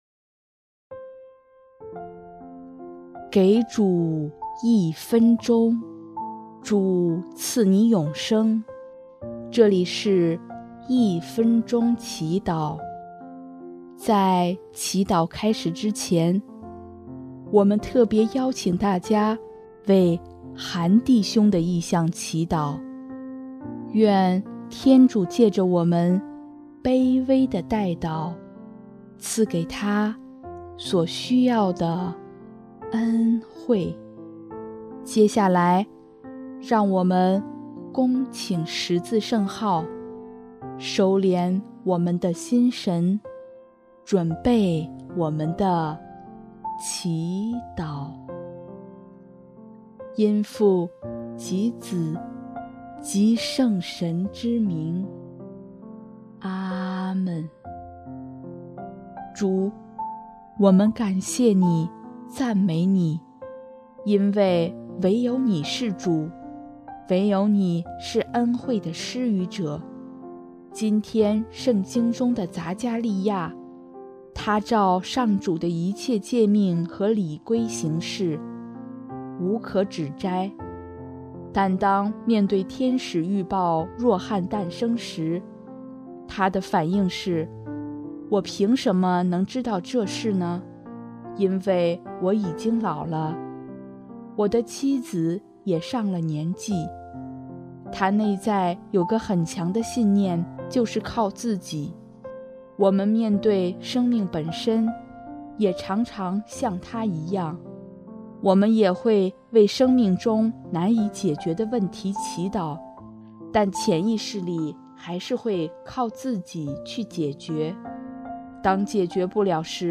【一分钟祈祷】|12月19日 上主是恩惠的施予者